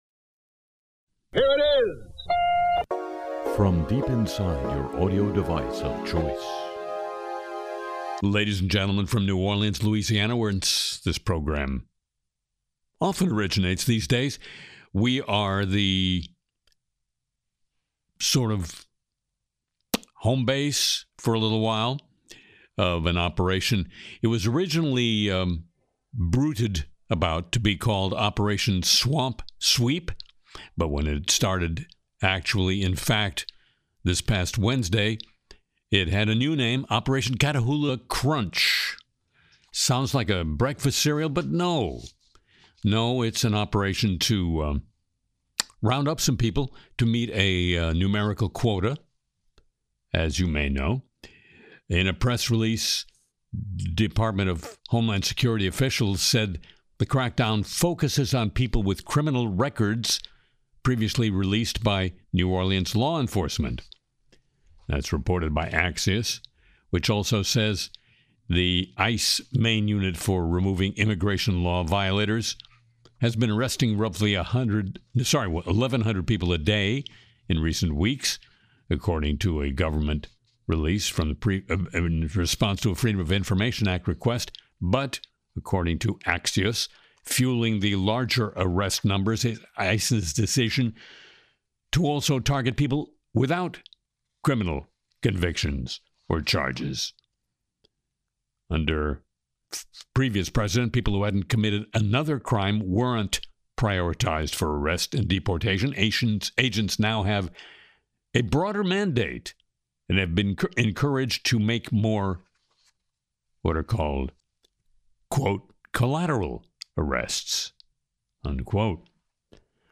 Harry sings “American Bitcoin,” spoofs Trump on Truth Social, covers New Orleans’ Catahoula Crunch, LA’s Olympic volunteer call, microplastic-eating crickets, and penguins starving.